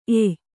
♪ ey